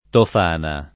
tof#na; non t0-] top. f. (Ven.) — tre cime: la Tofana di Roces [